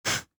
deer-v2.ogg